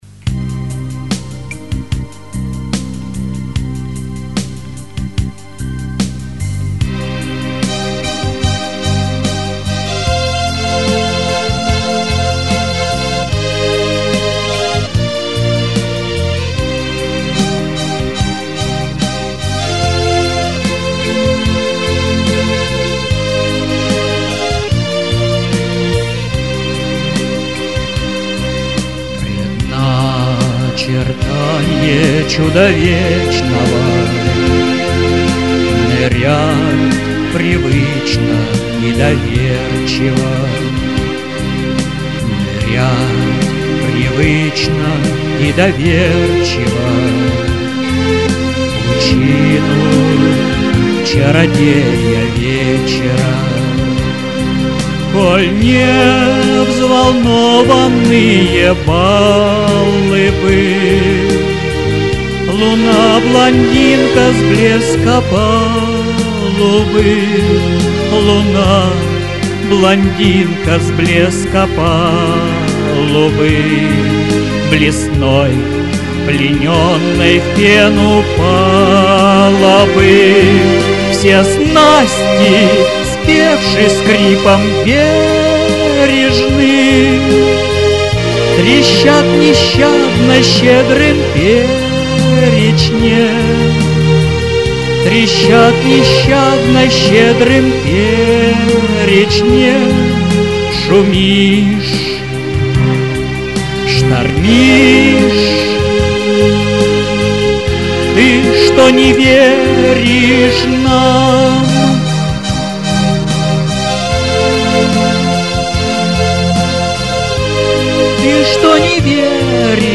Песни